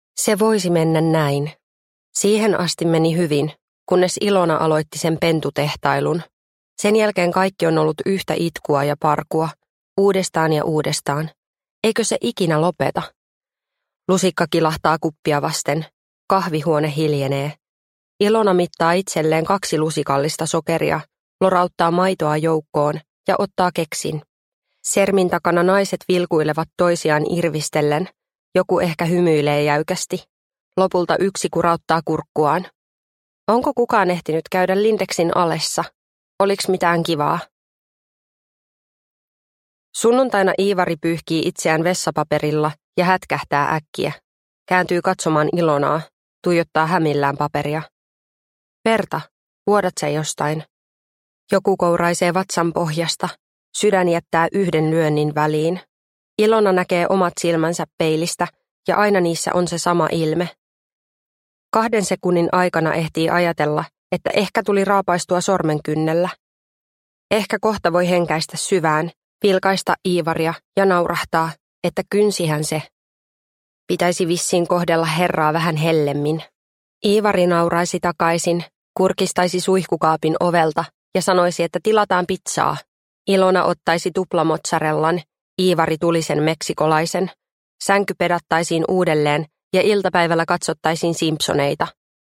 Puolinainen – Ljudbok – Laddas ner